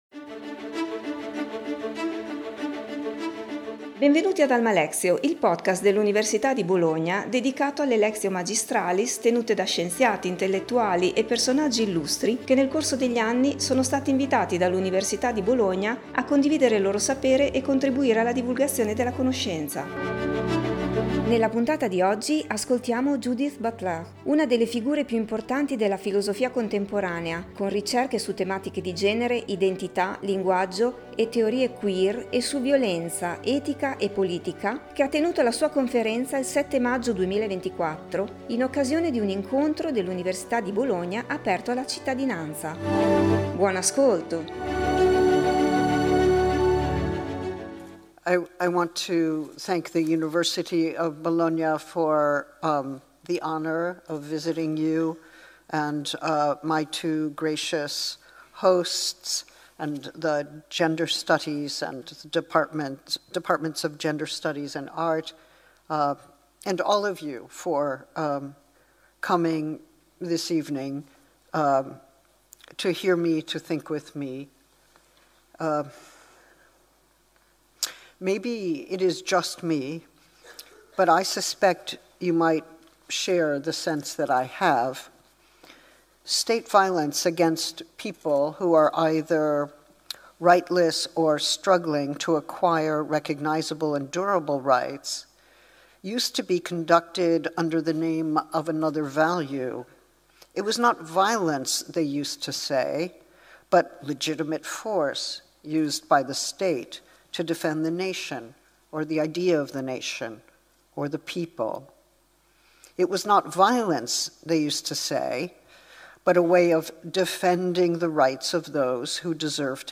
Judith Butler, una delle figure più importanti della filosofia contemporanea, e che ha concentrato le sue ricerche sulle tematiche di genere, identità, linguaggio e teorie “queer” e su violenza, etica, e politica, ha tenuto la sua conferenza il 7 maggio 2024 in occasione di un incontro dell’Università di Bologna aperto alla cittadinanza.